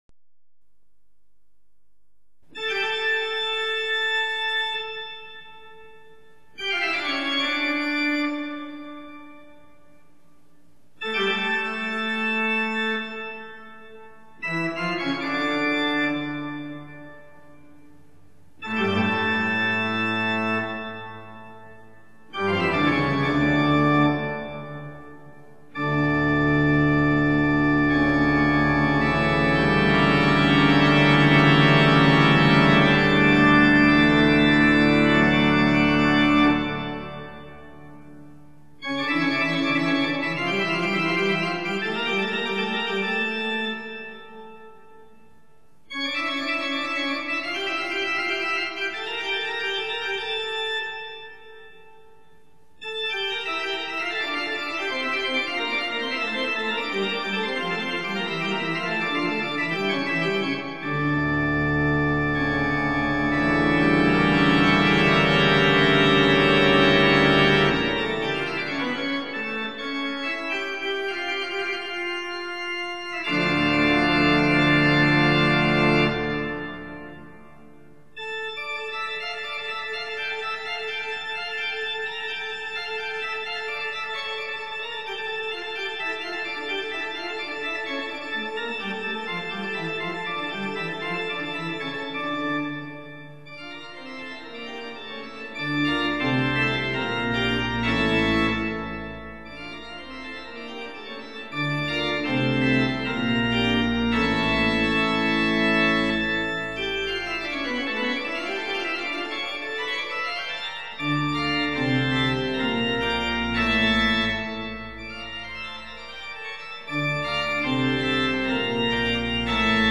大家常聽到的音樂 可能不知道是何樂器演奏的 今天就來介紹一種非常大型的樂器 - 管風琴(Organ or Orgah)
管風琴(Organ or Orgah)